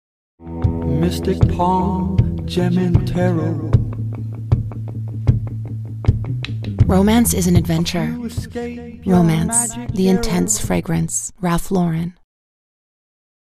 Female
Approachable, Assured, Confident, Conversational, Corporate, Energetic, Engaging, Natural
Microphone: Rode Nt1-A